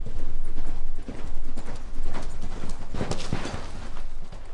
奔腾的马
描述：疾驰在乡下的马。 Foley，从头开始制作